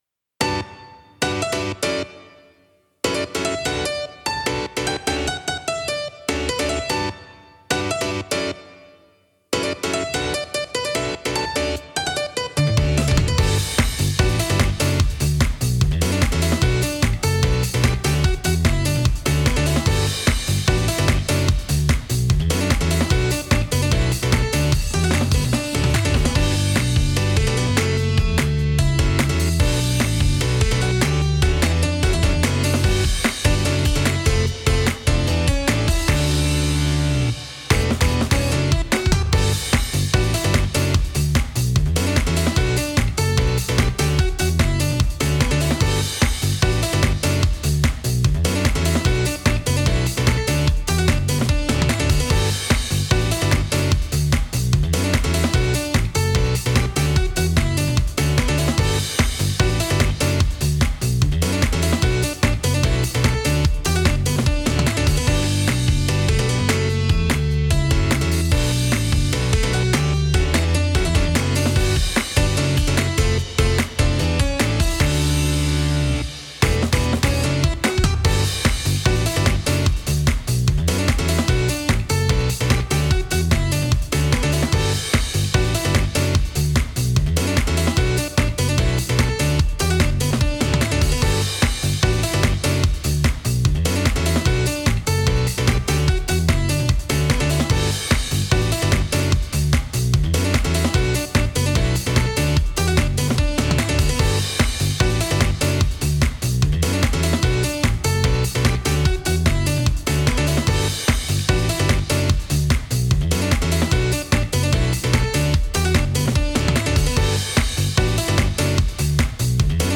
リリースカットピアノ/かっこいい/おしゃれ